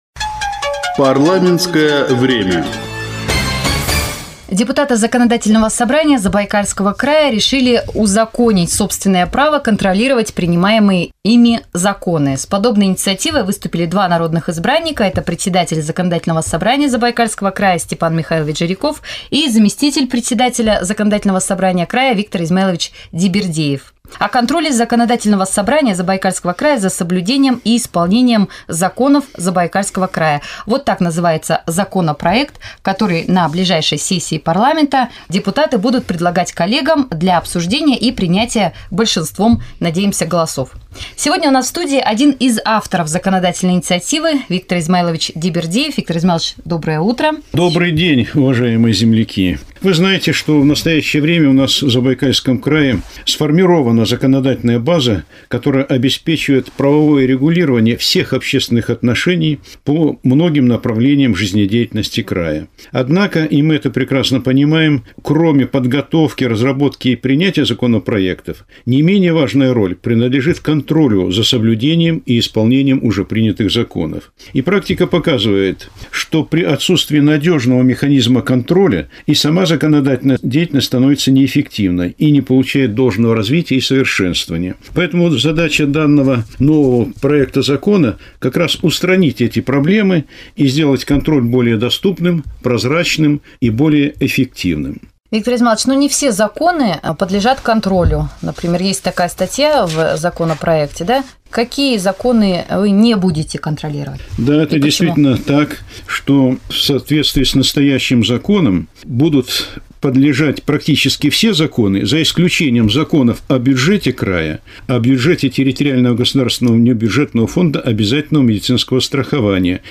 Заместитель председателя Законодательного Собрания края Виктор Дибирдеев прокомментировал законопроект, который 31 мая будет рассмотрен депутатами в первом чтении.